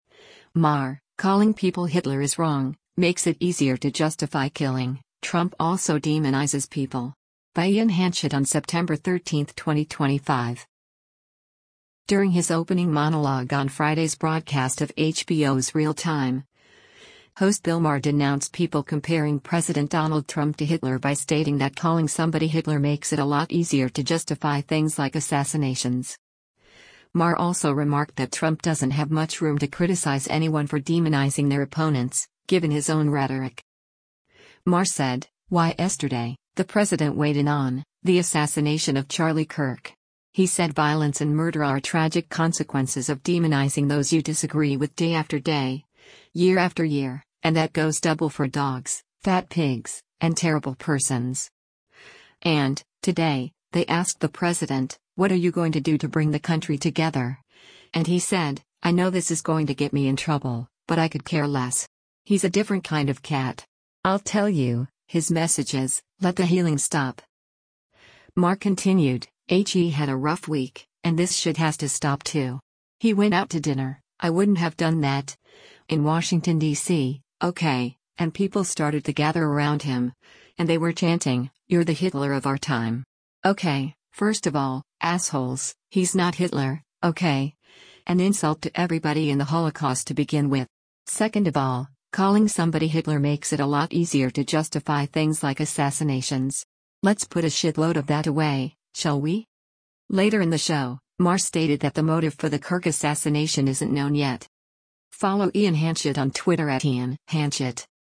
During his opening monologue on Friday’s broadcast of HBO’s “Real Time,” host Bill Maher denounced people comparing President Donald Trump to Hitler by stating that “calling somebody Hitler makes it a lot easier to justify things like assassinations.” Maher also remarked that Trump doesn’t have much room to criticize anyone for demonizing their opponents, given his own rhetoric.